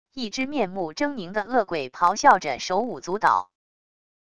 一只面目狰狞的恶鬼咆哮着手舞足蹈wav音频